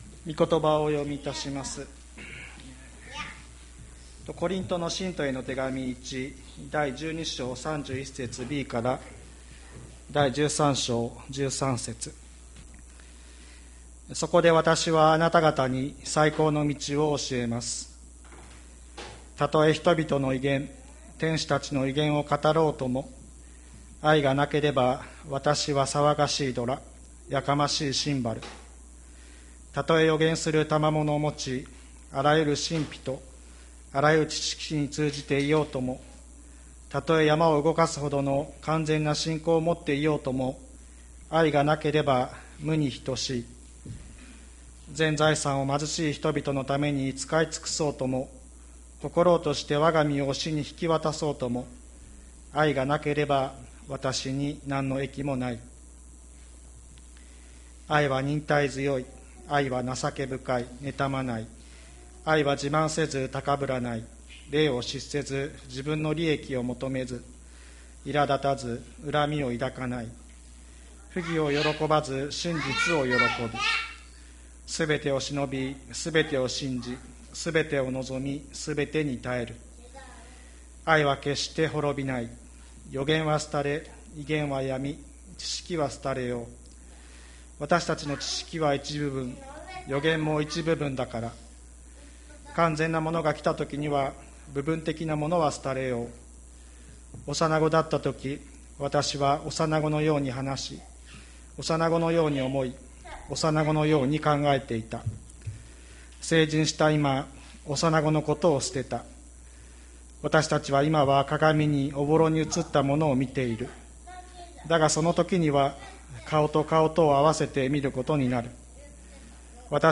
2020年07月05日朝の礼拝「愛の賛歌」吹田市千里山のキリスト教会
千里山教会 2020年07月05日の礼拝メッセージ。